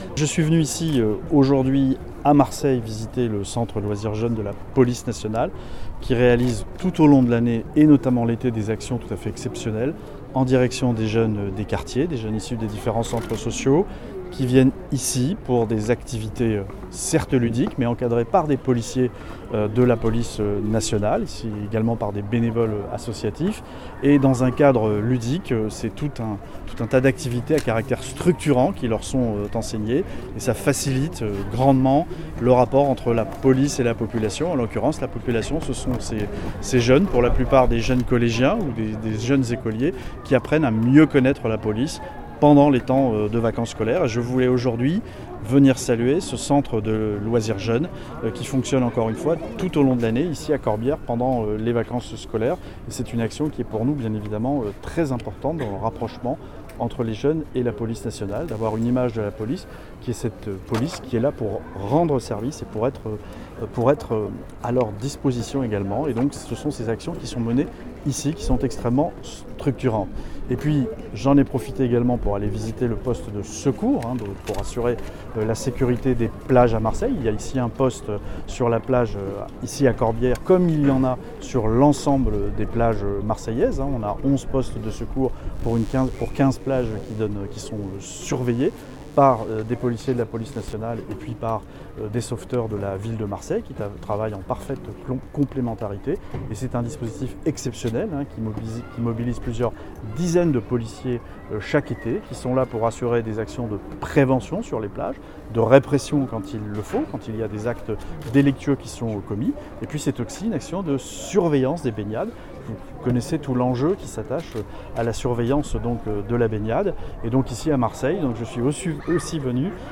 son_copie_petit-379.jpgEntretien avec Laurent Nuñez